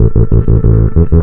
FINGERBSS8-R.wav